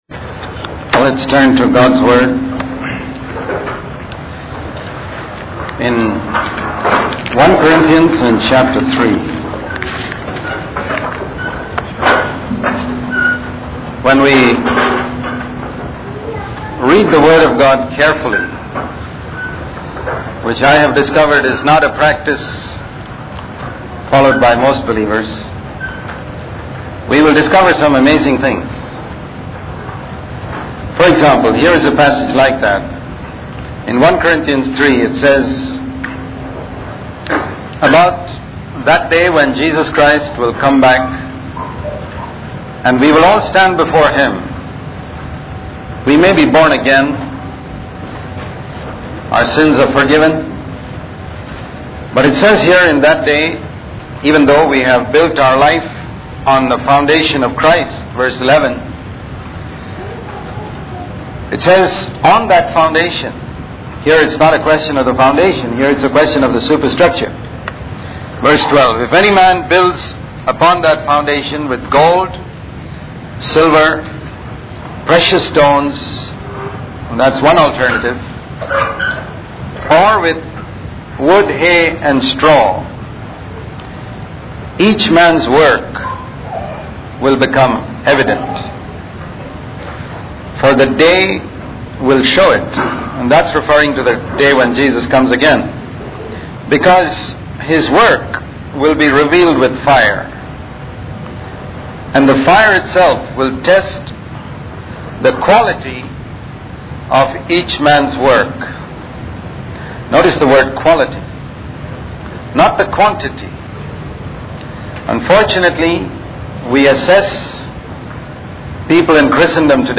In this sermon, the speaker emphasizes the importance of controlling our bodies and living a life of holiness. He references 1 Corinthians 9:27, where Paul states that he makes his body do what it should, not what it wants to do. The speaker highlights the temptation to indulge in sinful desires and urges listeners to resist these temptations.